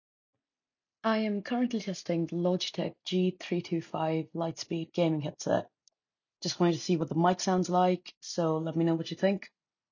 Instead, the G325 Lightspeed features a built-in omnidirectional mic, and Logitech says that this “beamforming microphone is designed so your voice can be heard clearly.”
As you can hear in the clip above, the G325 Lightspeed’s mic can be described as merely okay. My voice sounds a little thin, and the vocal timbre feels flat and hollow.